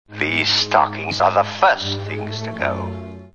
Computer Sounds